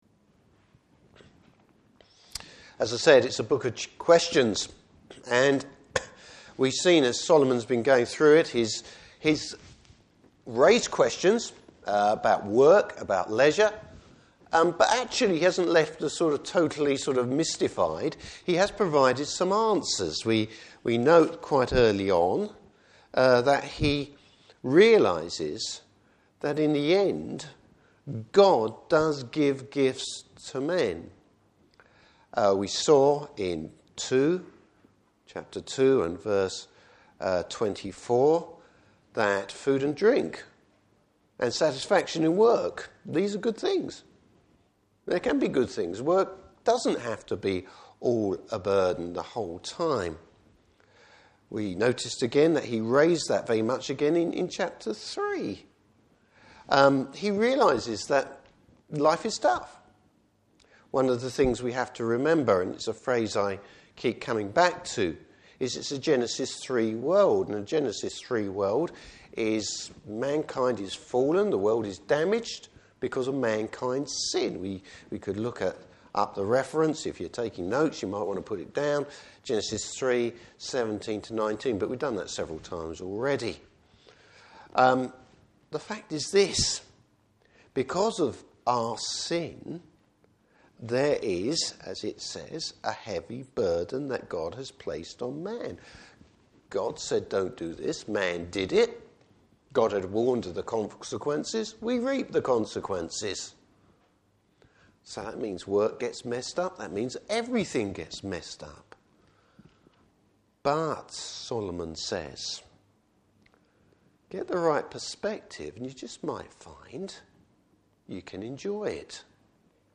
Service Type: Morning Service Bible Text: Ecclesiastes 5.